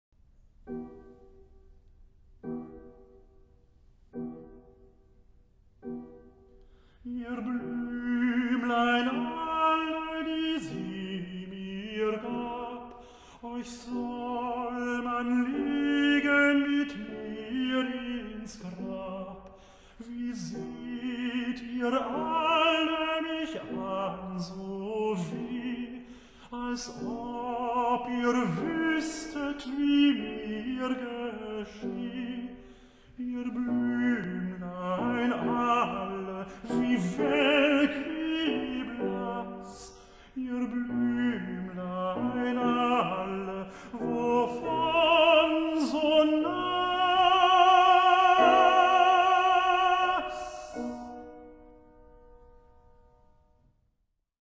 This phrase features a modulation or tonicization of a closely related key.
Write the vocal melody (it begins with an upbeat sixteenth) and the piano bass line.
melody/harmony mp3